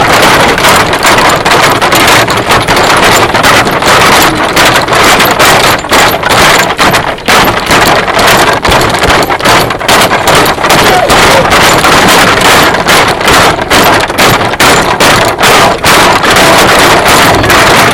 Questo suono si trova nel Parco della Dogana, proprio dove, in genere, salgono solo i bambini!
6 anni Indizi dei bambini È un rumore traballante che si sente un po’ legnoso; si suona sbattendo i piedi.